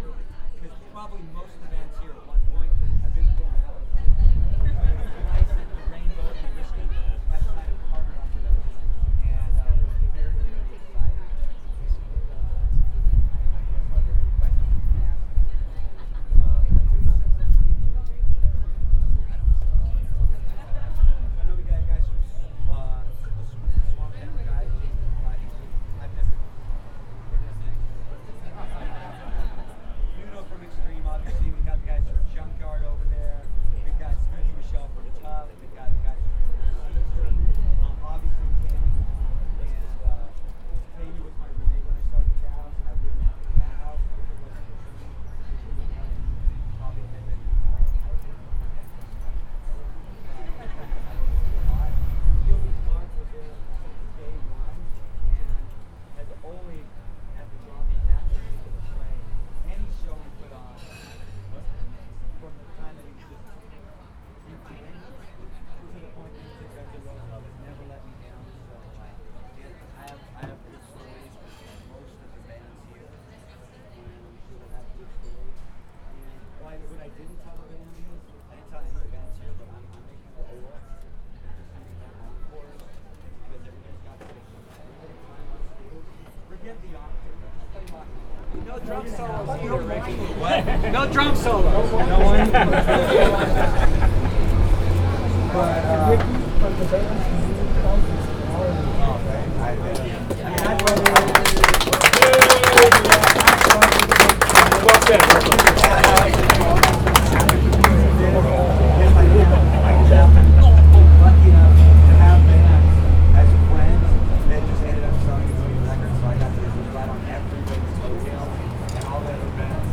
During the CATHOUSE LIVE press conference that took place at the Rainbow this afternoon, CRN interviewed Riki Rachtman and Cathouse Live band members including (links are audio files of the interviews):